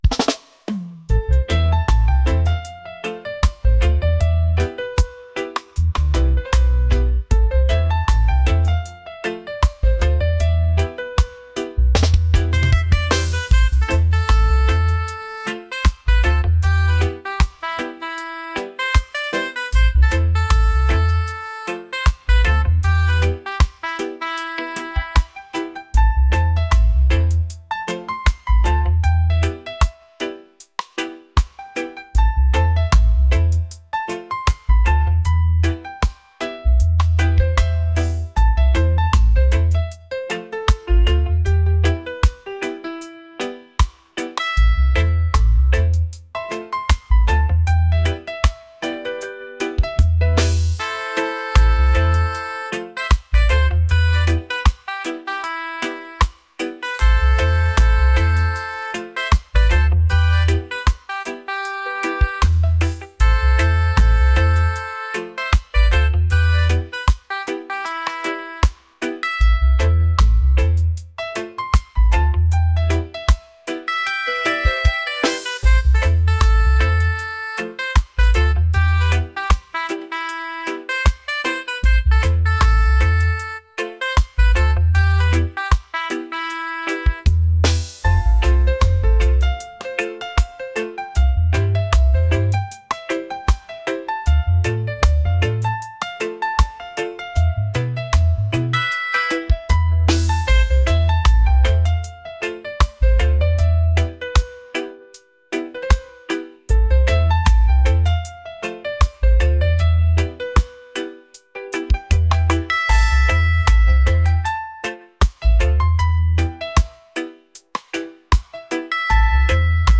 reggae | romantic | mellow